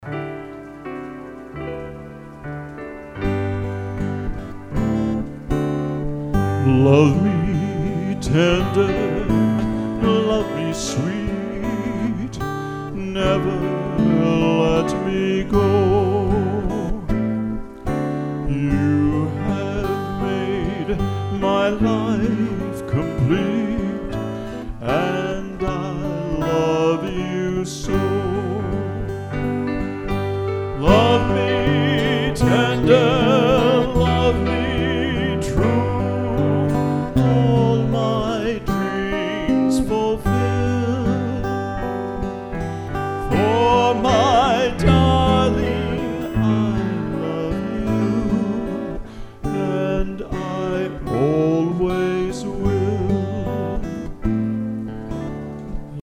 veteran eclectic rock group